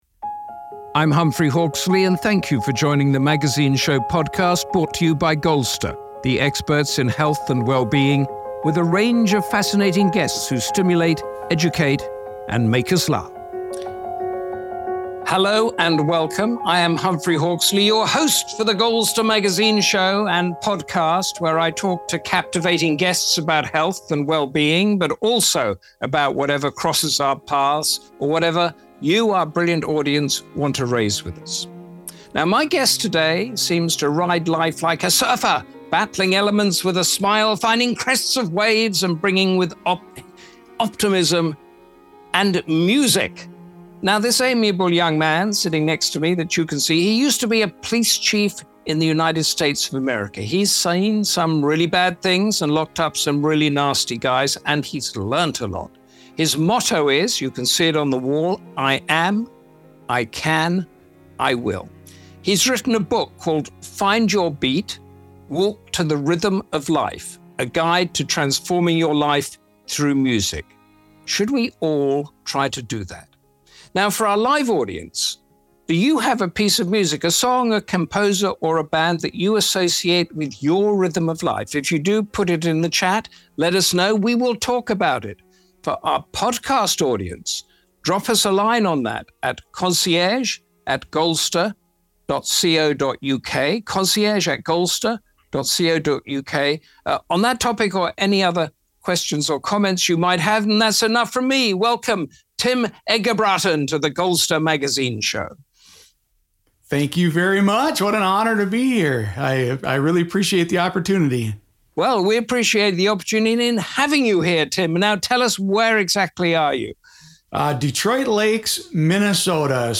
In conversation with Humphrey Hawksley.
Dance to the melody of optimism, and Humphrey will coax him into playing some tunes.